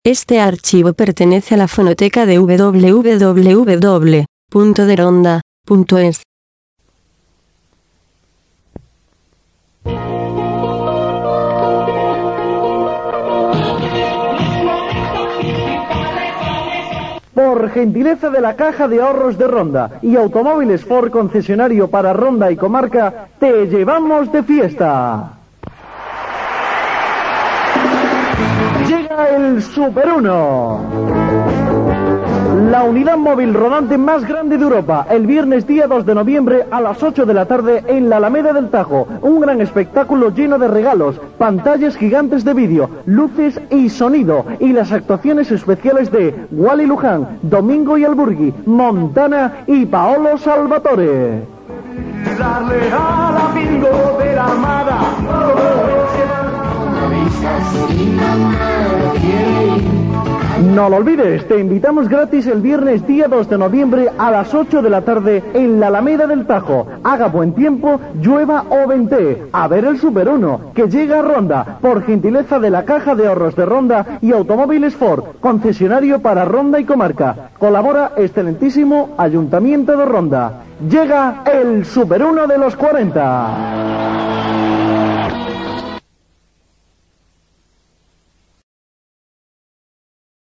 Seguidamente insertamos el anuncio que se emitía en Radio Coca Ser Ronda con motivo del "Súper 1".